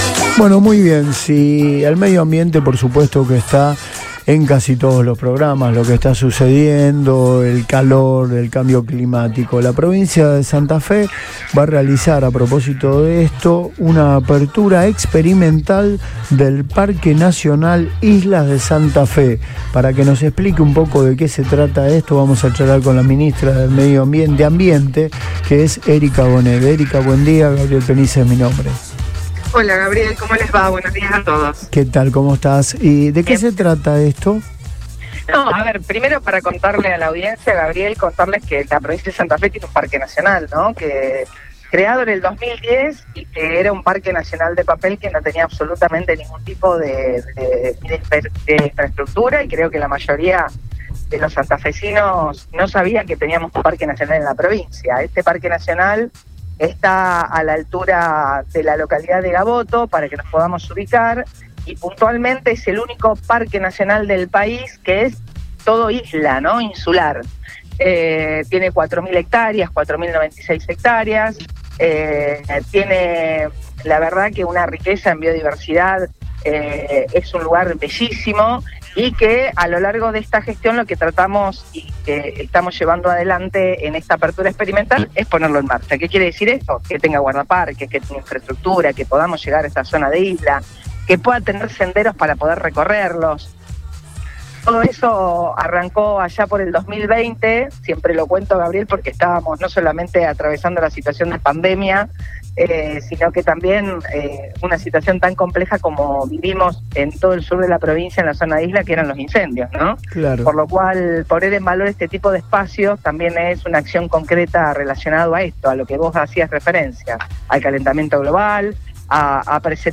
Erika-Gonnet.mp3